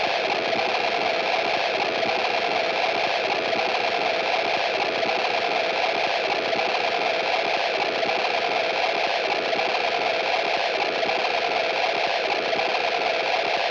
Warning: This artwork incorporates iAMF frequency elements intended for subconscious conditioning.
These ‘Infinity L00p’ soundscapes, crafted with precision, resonate with the listener’s subconscious, creating an auditory experience that lingers long after the final note.